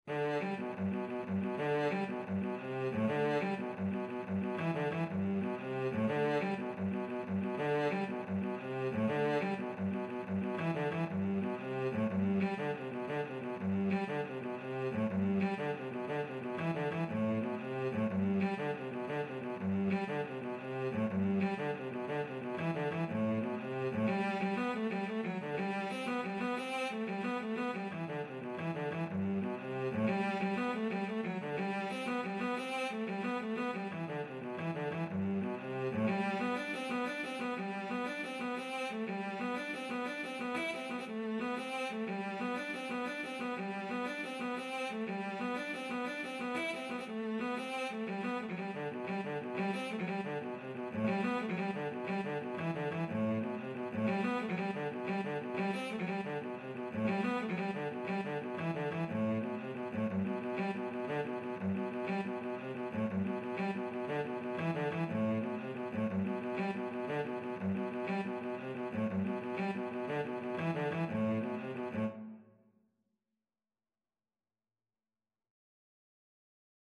Cello version
9/8 (View more 9/8 Music)
G3-E5
Cello  (View more Easy Cello Music)
Traditional (View more Traditional Cello Music)